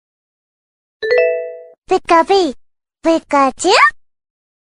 Cute Message Ringtone Download Baby Voice.
• Soft and Sweet Notification Sound
• Short and Clear Sound